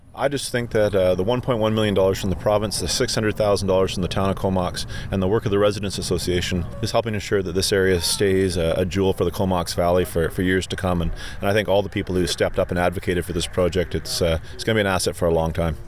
Local MLA Don McRae says the work is very important to the community.